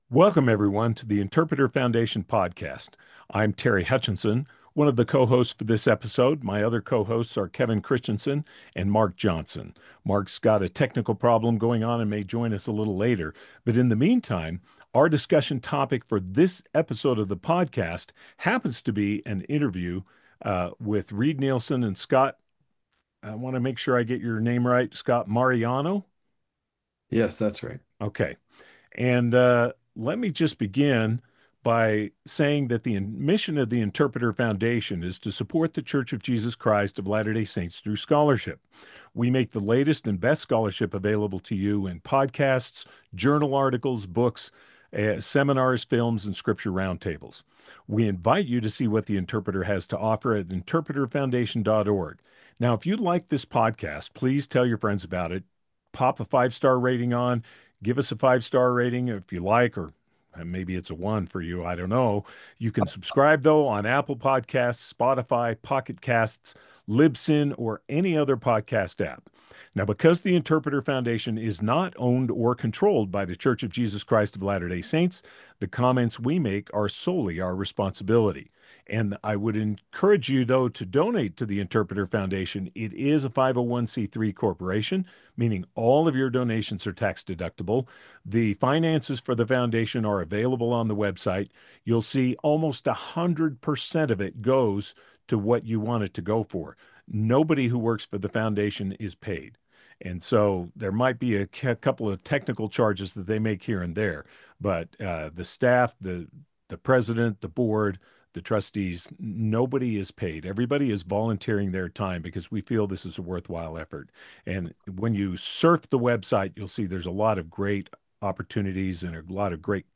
Podcast: Download The Interpreter Foundation Podcast is a weekly discussion of matters of interest to the hosts and guests of the show.